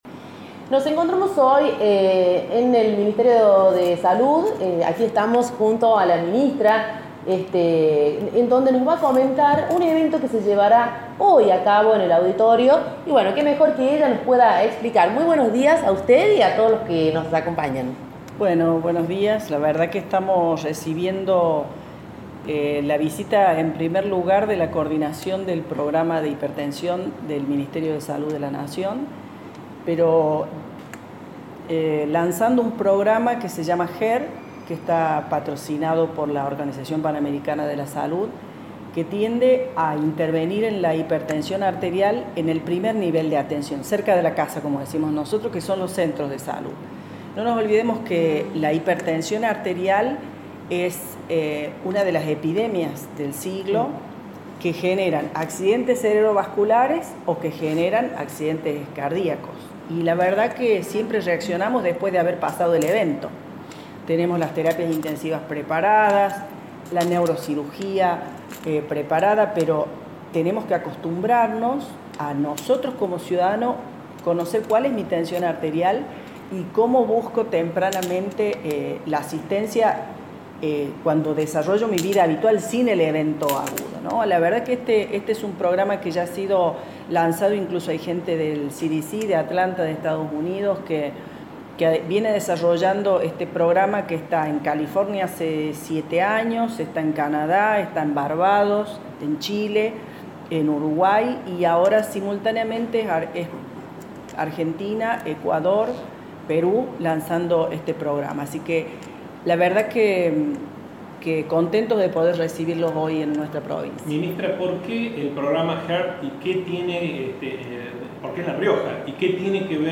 Las declaraciones de Díaz Bazán